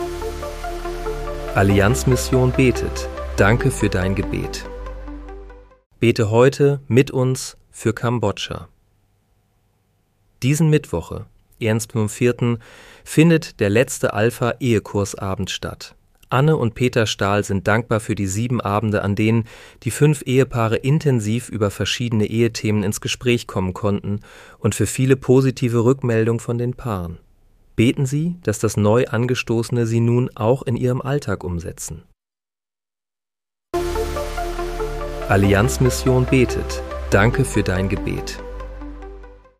Bete am 01. April 2026 mit uns für Kambodscha. (KI-generiert mit